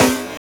CardiakSnare4.wav